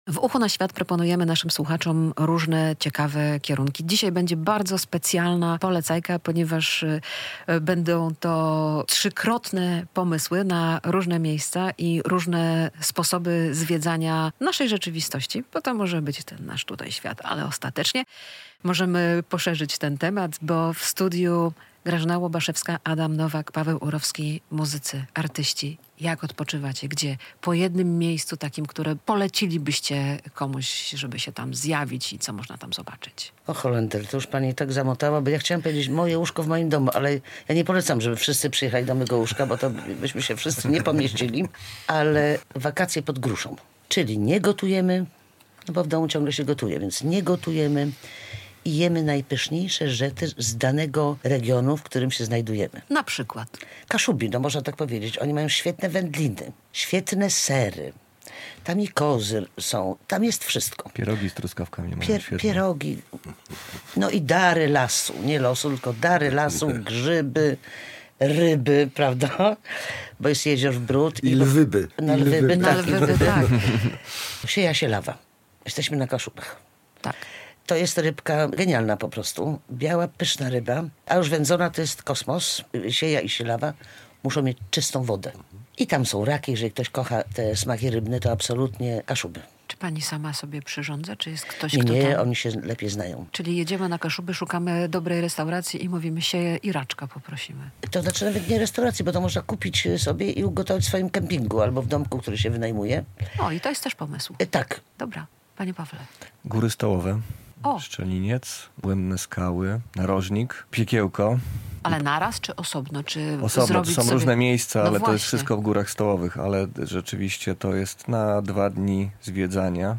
To jest rozmowa o miejscach, które mają duszę.